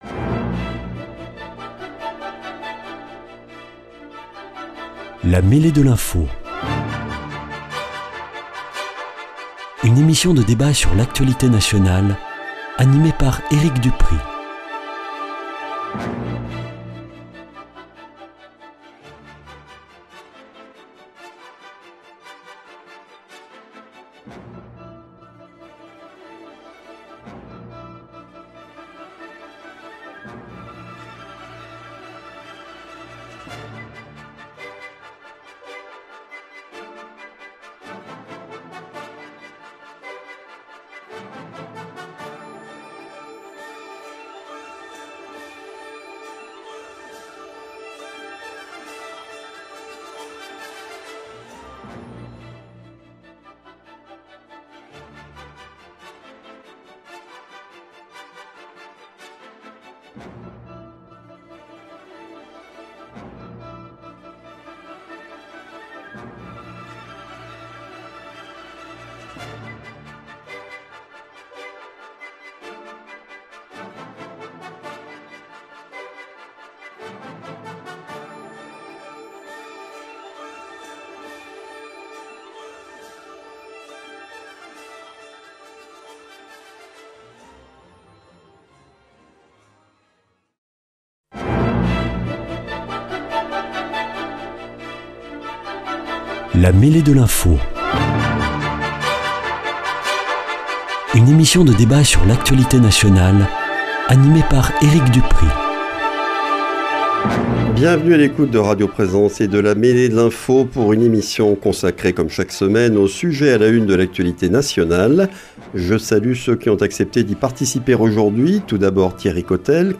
Invités : Thierry COTELLE, conseiller régional d'Occitanie, président national du Mouvement Républicain et Citoyen, dirigeant d'entreprise ; Pierre ESPLUGAS, universitaire, professeur de droit public, adjoint au maire de Toulouse, membre de LR